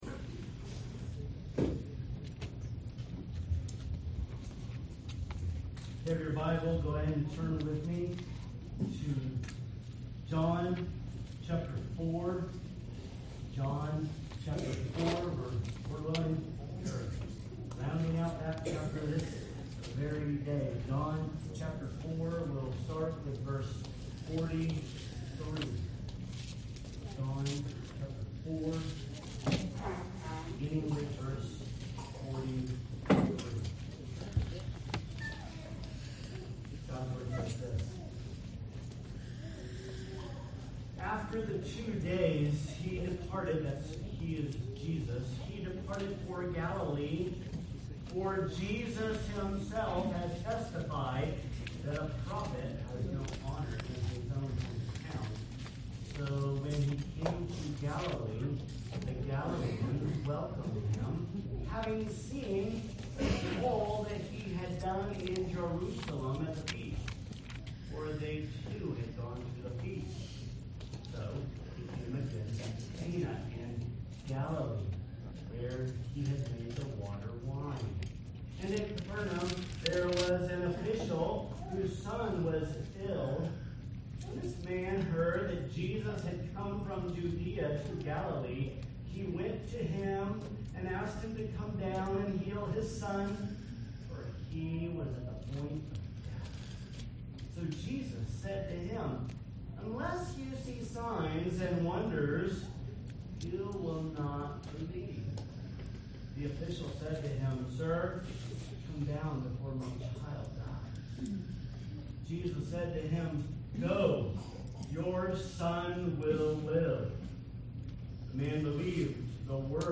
We apologize for the sound quality. We were having technical difficulties with the sound on this particular Sunday.